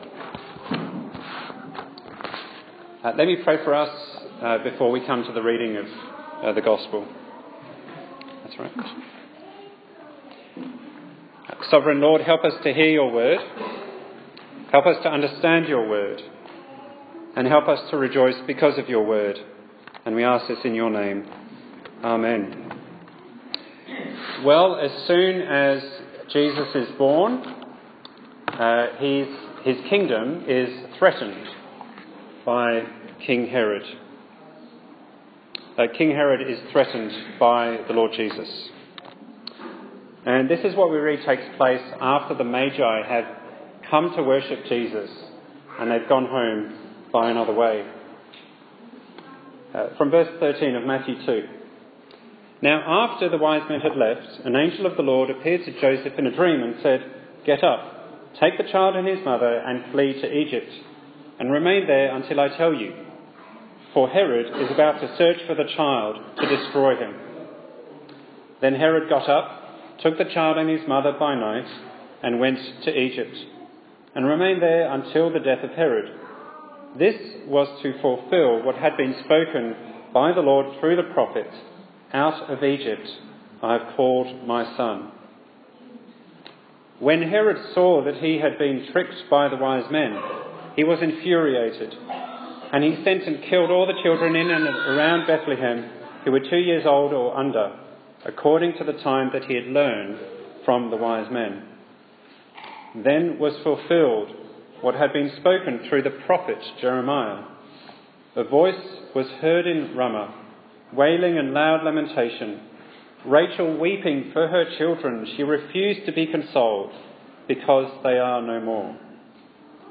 Bible Text: Matthew 2:13-23 | Preacher